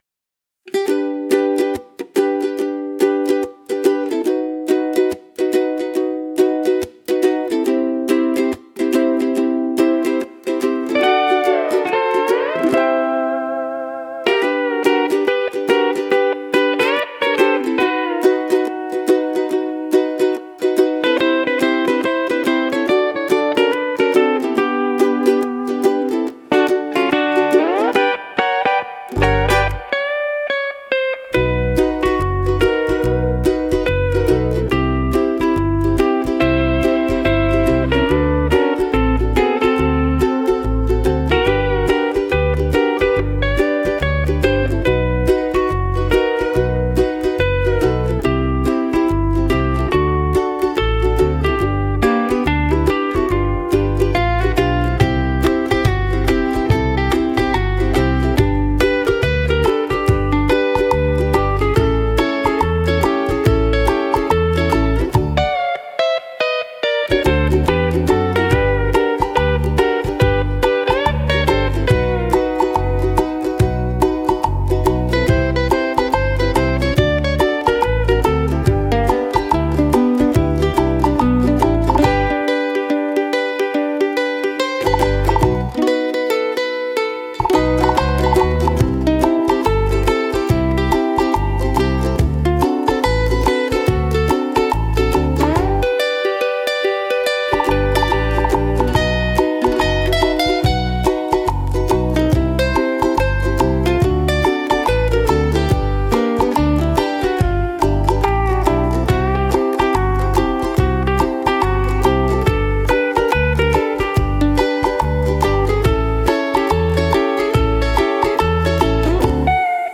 聴く人にゆったりとした安らぎや心地よさを届ける、穏やかで親しみやすいジャンルです。